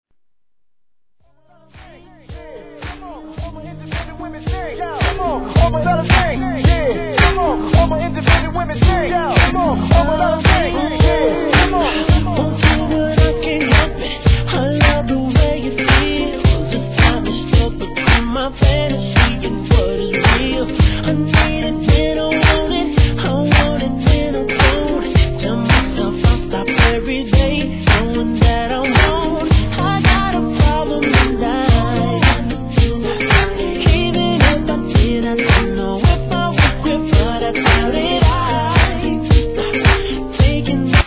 1. HIP HOP/R&B
(BPM 110)